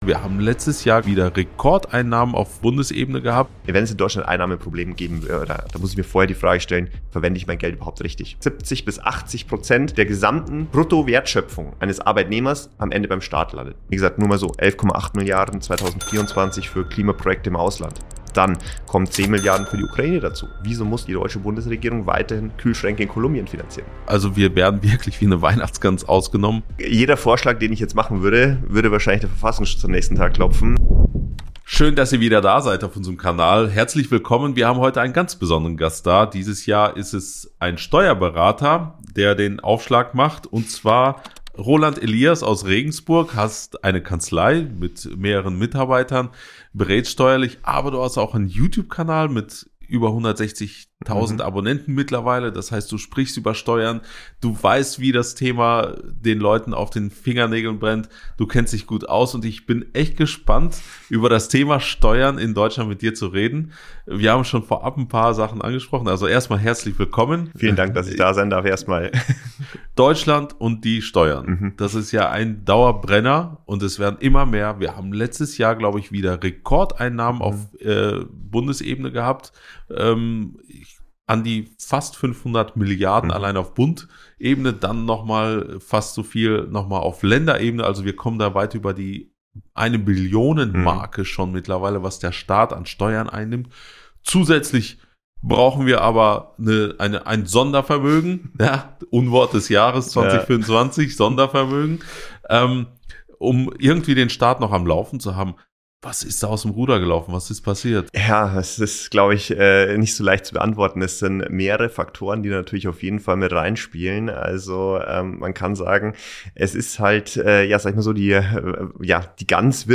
Junge Freiheit Interview Wohin verschwinden Milliarden?